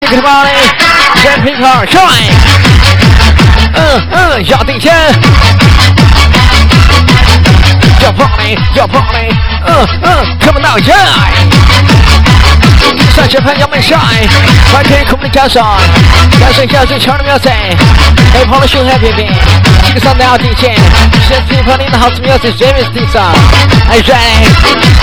分类: DJ铃声